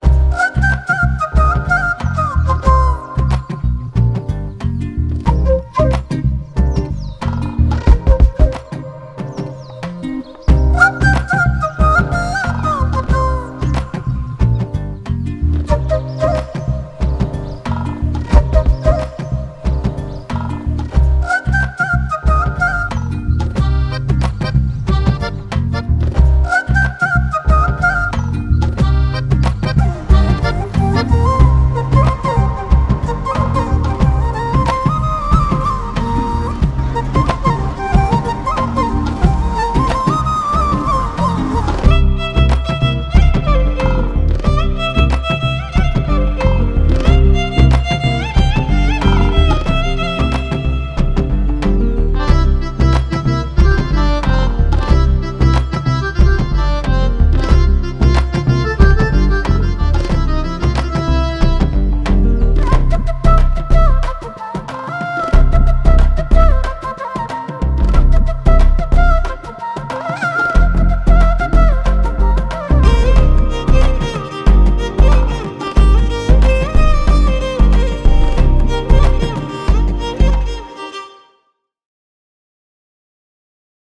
without dialogues and unwanted disturbances
love BGM